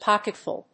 音節pock・et・ful 発音記号・読み方
/pάkɪtf`ʊl(米国英語), ˈpɑ:kʌˌtfʊl(英国英語)/